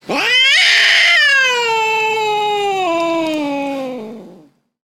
Divergent / mods / Soundscape Overhaul / gamedata / sounds / monsters / cat / c_die_1.ogg